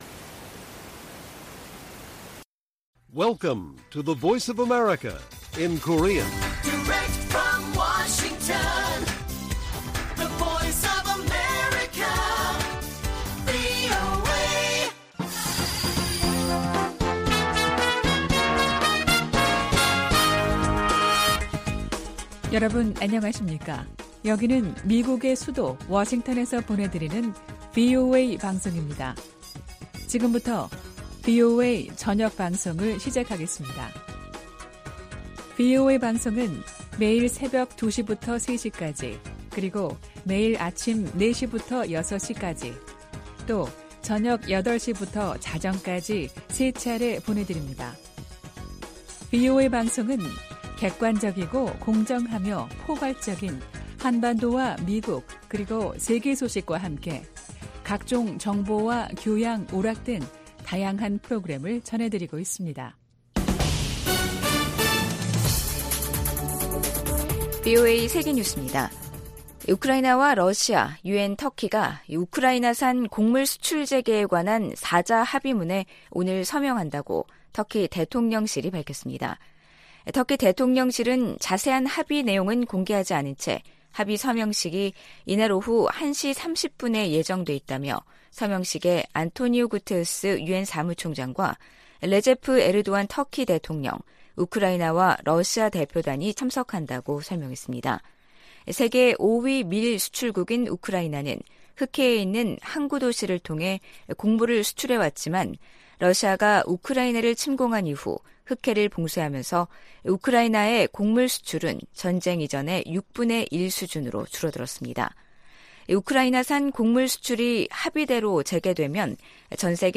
VOA 한국어 간판 뉴스 프로그램 '뉴스 투데이', 2022년 7월 22일 1부 방송입니다. 한국 국방부는 대규모 미-한 연합연습과 야외기동훈련을 올해부터 부활시킬 방침이라고 밝혔습니다. 미국은 한국과 일본의 핵무장을 절대 지지하지 않을 것이라고 고위 관리가 전망했습니다. 미 공화당 상원의원들이 로이드 오스틴 국방장관에게 인도태평양 지역 미사일 배치를 위해 한국 등 동맹국들과 협력하라고 촉구했습니다.